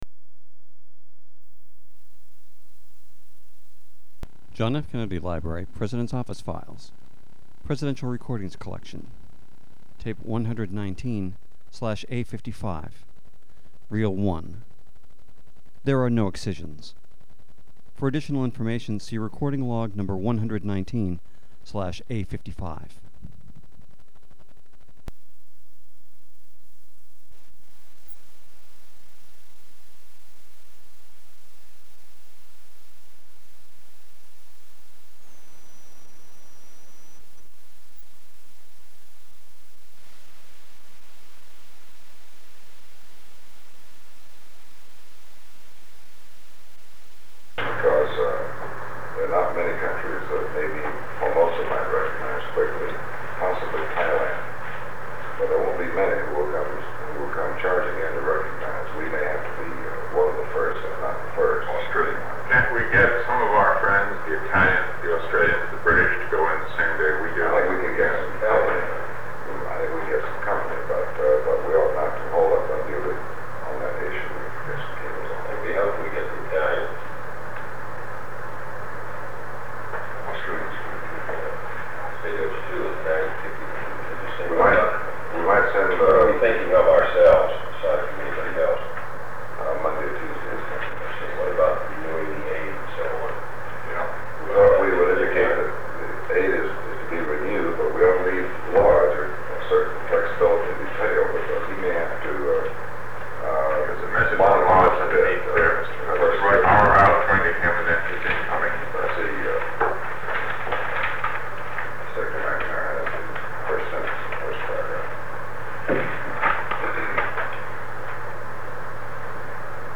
Sound recording of a meeting about Vietnam held on November 2, 1963, between President John F. Kennedy, Special Assistant to the President for National Security Affairs McGeorge Bundy, Military Advisor to the President General Maxwell Taylor, Under Secretary of State for Political Affairs W. Averell Harriman, and Assistant Secretary of State for Far Eastern Affairs Roger Hilsman.
Secret White House Tapes | John F. Kennedy Presidency Meetings: Tape 119/A55.